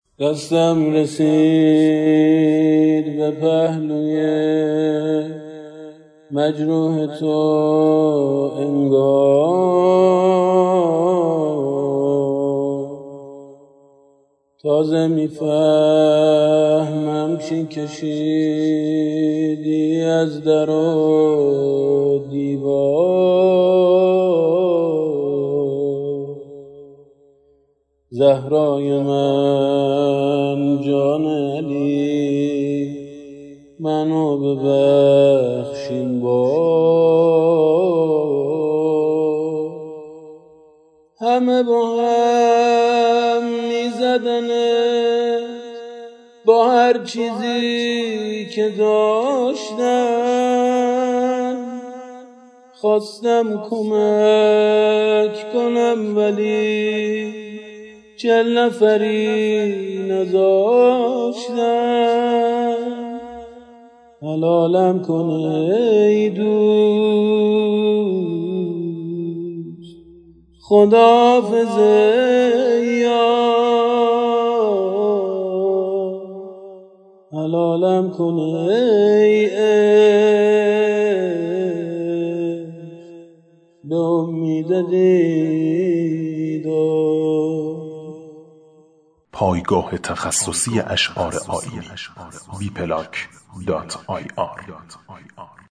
زمزمه - - -